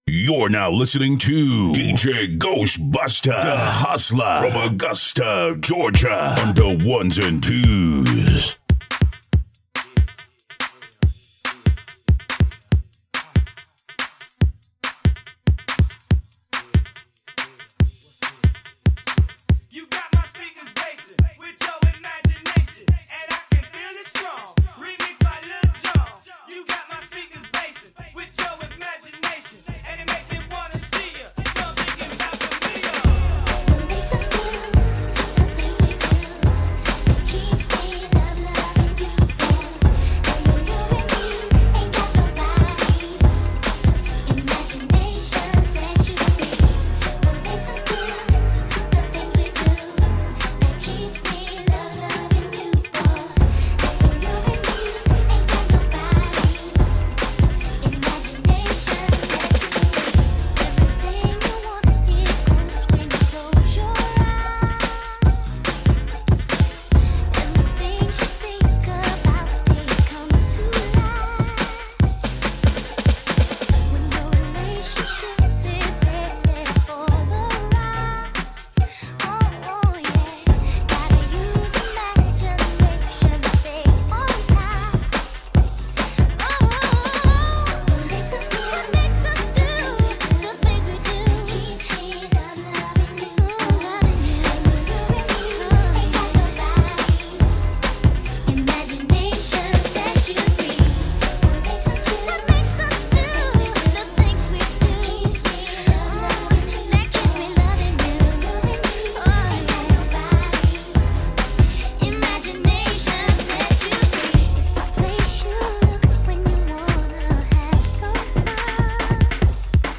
Hiphop
Quick Mini Bass Mix